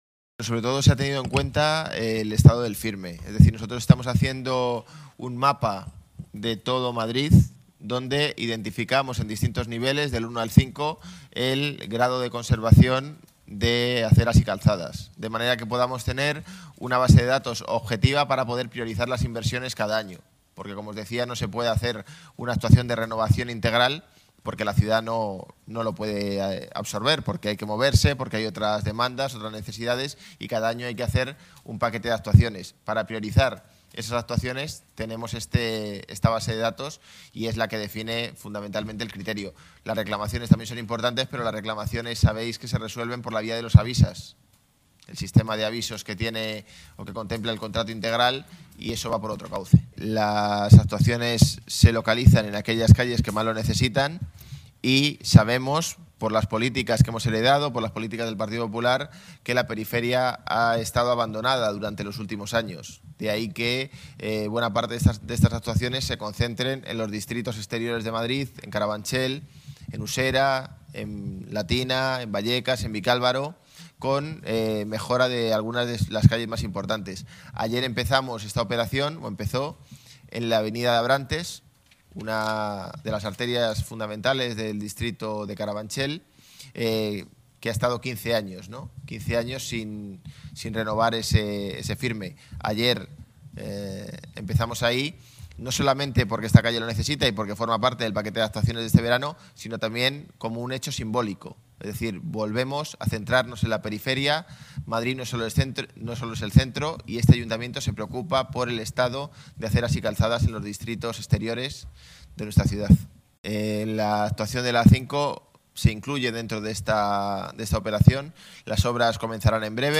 Nueva ventana:José Manuel Calvo, delegado Desarrollo Urbano Sostenible.